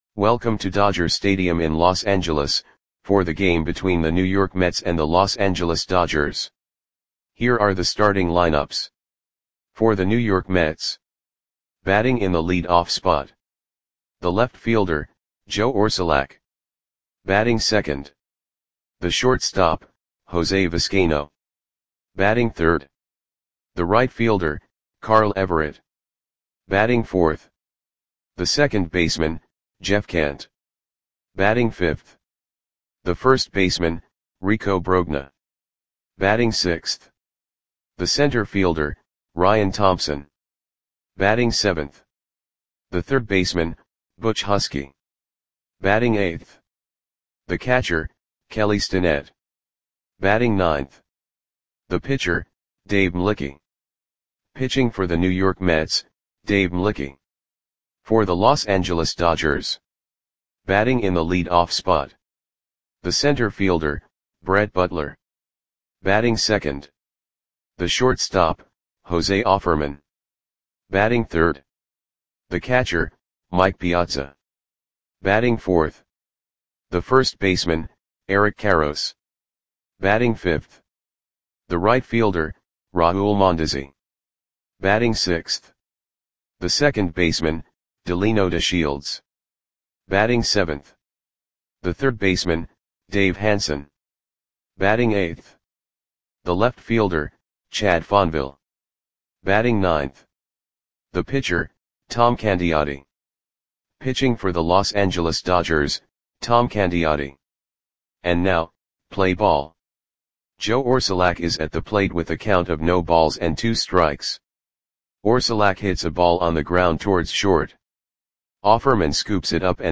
Lineups for the Los Angeles Dodgers versus New York Mets baseball game on August 30, 1995 at Dodger Stadium (Los Angeles, CA).
Click the button below to listen to the audio play-by-play.